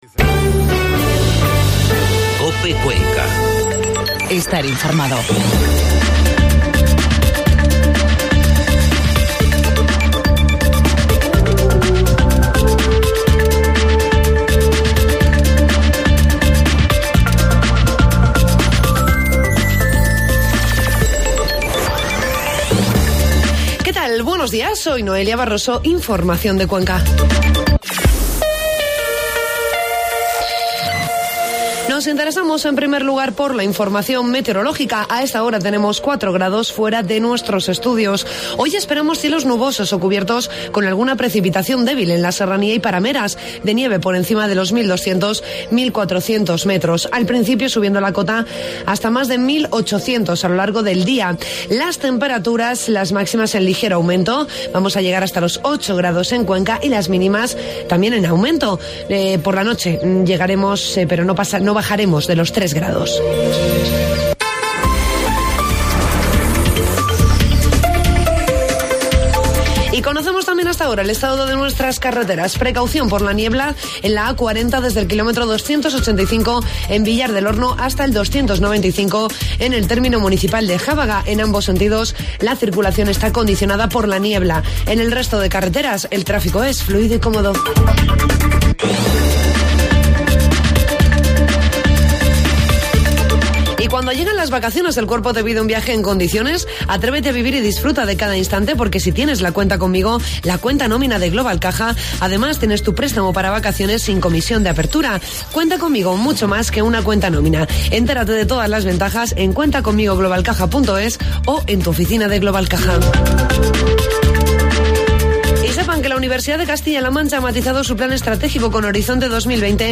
Informativo matinal COPE Cuenca 14 de febrero